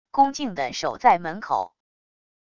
恭敬地守在门口wav音频生成系统WAV Audio Player